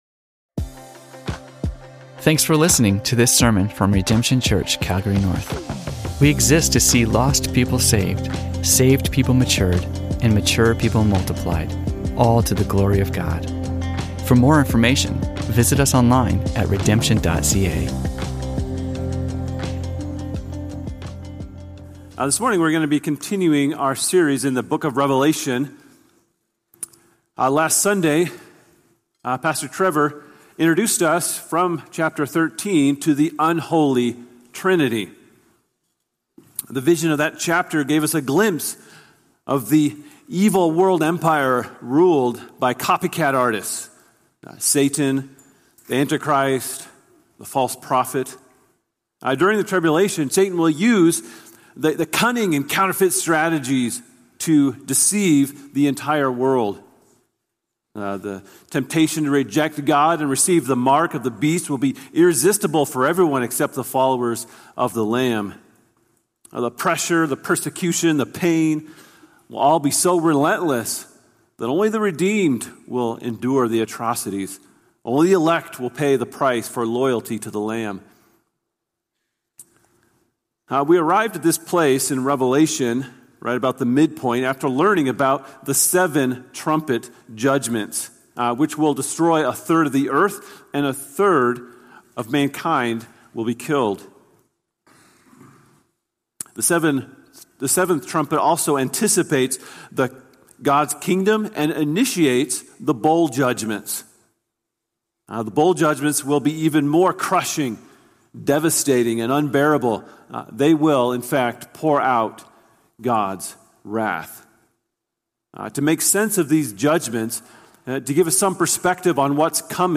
Sermons from Redemption Church Calgary North